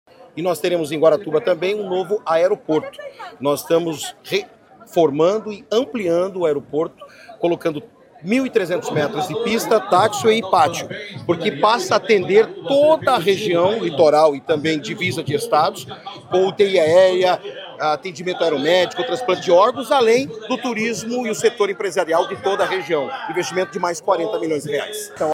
Sonora do secretário Estadual da Infraesturura e Logística, Sandro Alex, sobre a reforma do Aeródromo de Guaratuba